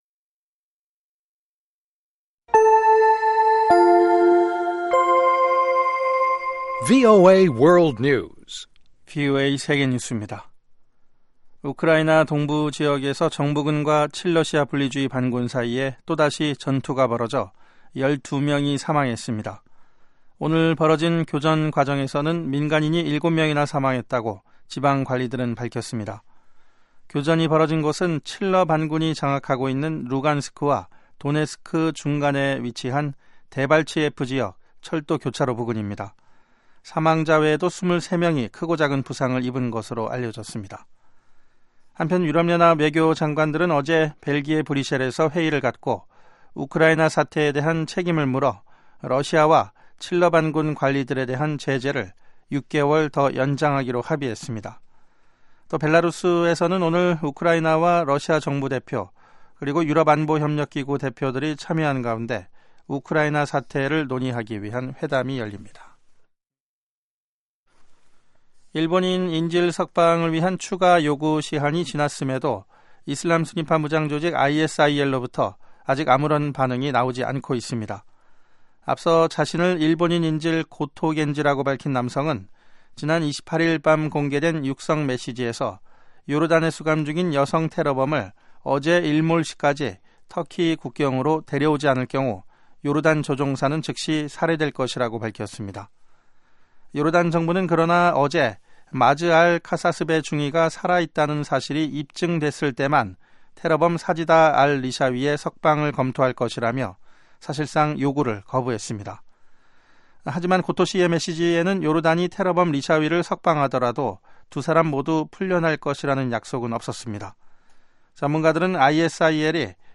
VOA 한국어 방송의 시사 교양 프로그램입니다.